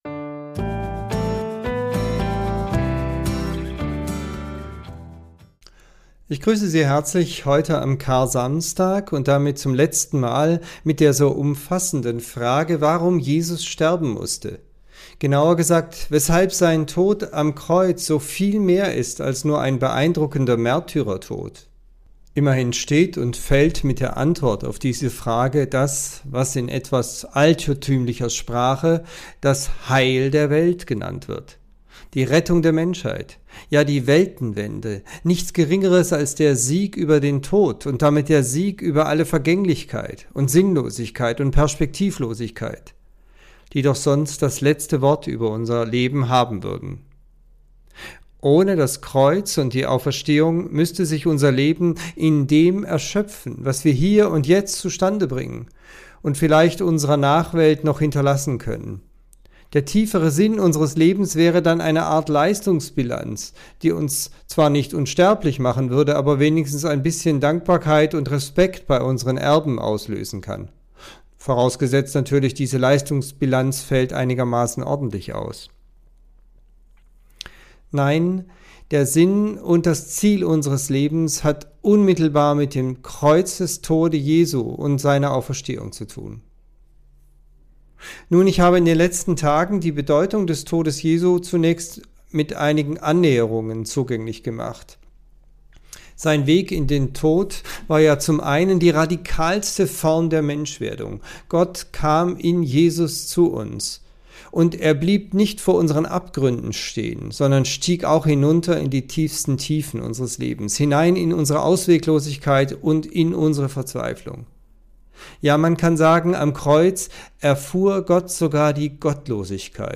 Tübinger Telefonandacht zur Tageslosung